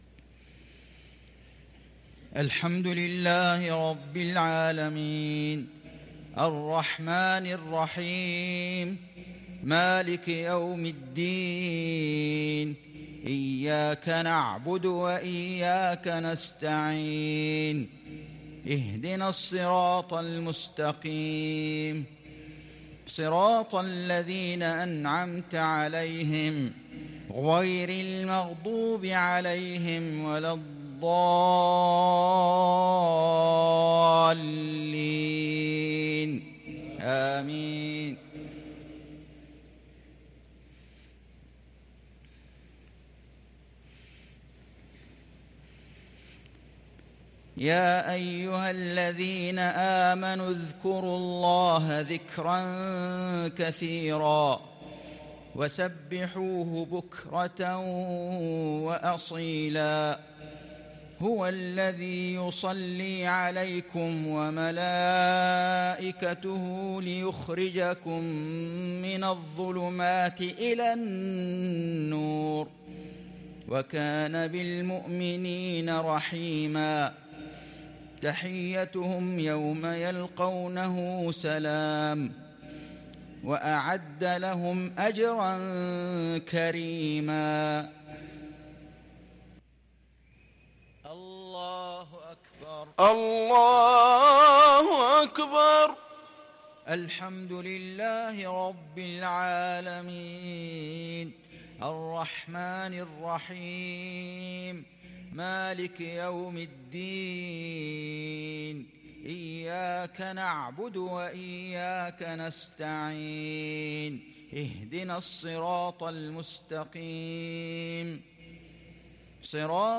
صلاة المغرب ٦ محرم ١٤٤٤هـ من سورة الأحزاب | Maghrib prayer from Surah al-Ahzab 4-8-2022 > 1444 🕋 > الفروض - تلاوات الحرمين